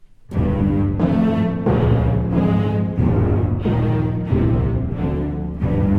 Version choisie pour les extraits : prestation de Anna Vinnitskaya et l'Orchestre National de Belgique sous la direction de Gilbert Varga lors de la finale du Concours Reine Elisabeth et avec lequel elle remporta le 1er Prix en 2007.
Pesante. Les vents et cordes ouvrent bruyamment le mouvement par une formule cadentielle persistante,